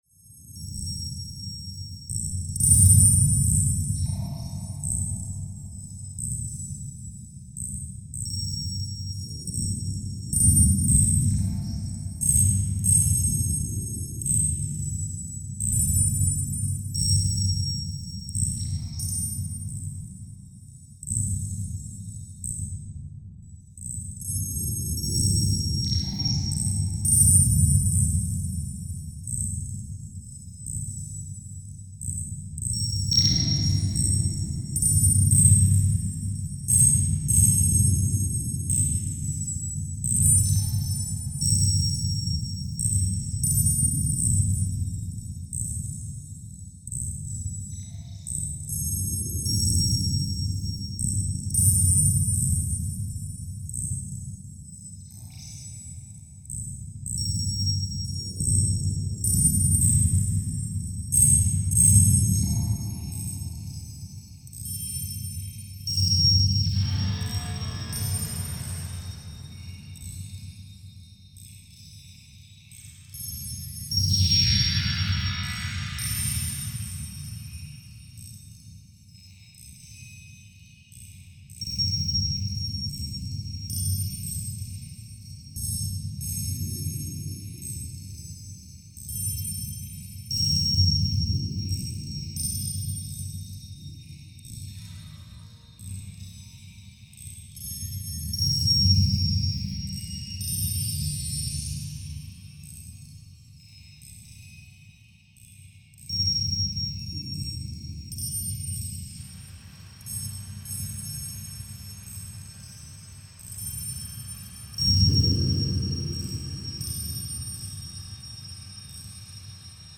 electroacoustic music